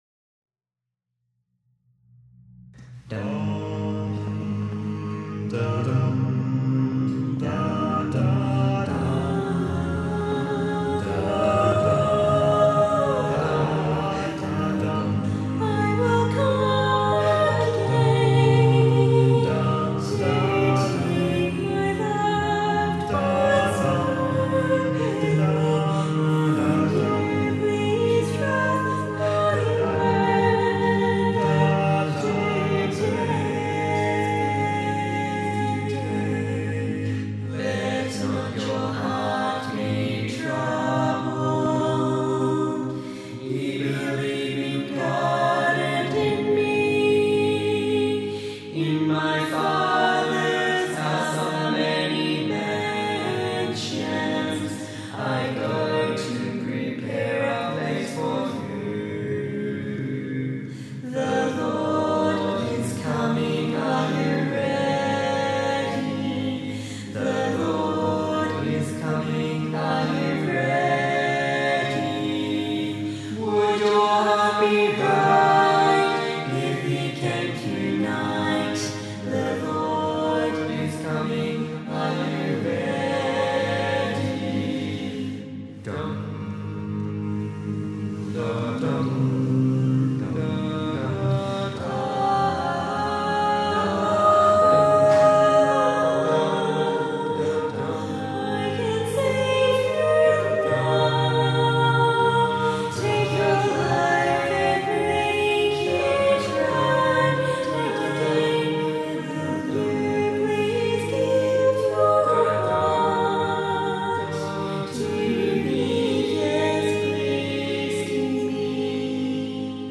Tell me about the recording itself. Music Camp 2017 Practice Recordings